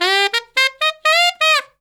Index of /90_sSampleCDs/Zero-G - Phantom Horns/SAX SOLO 2